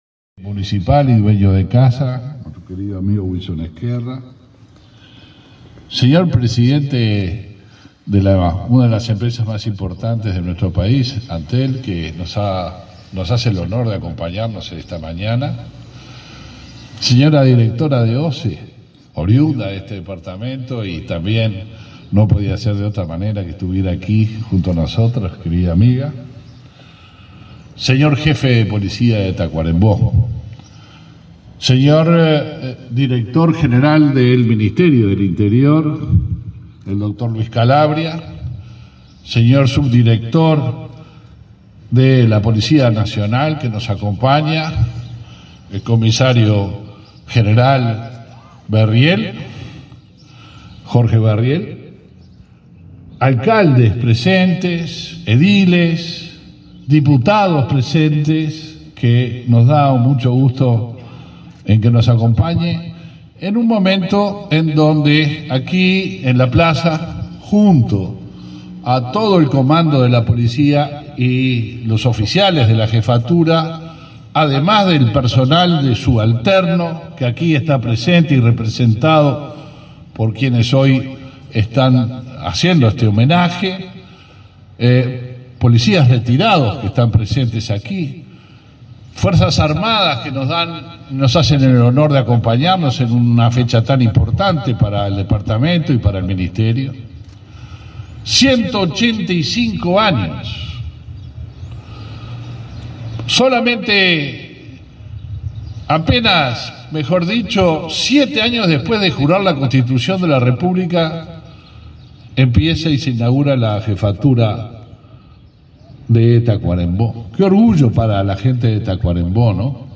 Palabras del ministro del Interior, Luis Alberto Heber
El ministro del Interior, Luis Alberto Heber, participó, este 12 de agosto, en el 185.° aniversario de la Jefatura de Tacuarembó.
heber oratoria .mp3